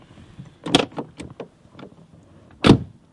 一记重击
描述：一记重击。 很棒的拳击声。
标签： 冲击 粉碎 zoom H2 砰的一声 重拳 击打 拳击
声道立体声